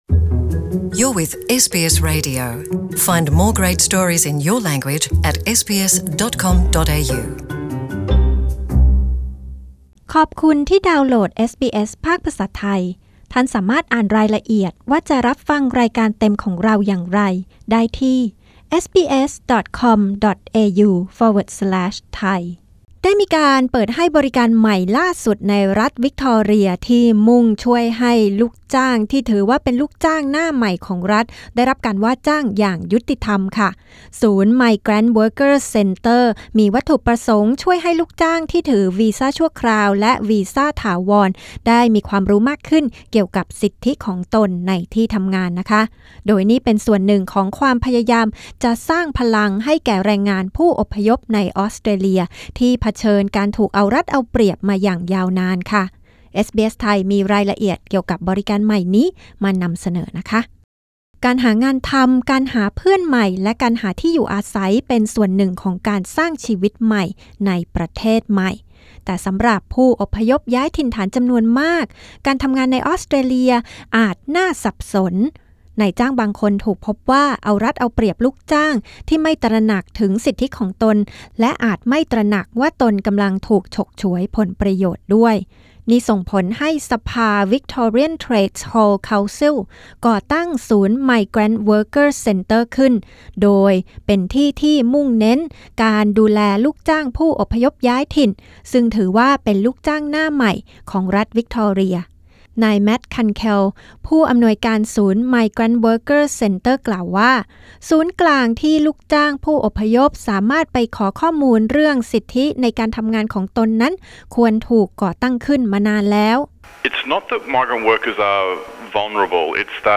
โปรดกดปุ่ม (▶) ด้านบนเพื่อรับฟังสารคดีเรื่องนี้เป็นภาษาไทย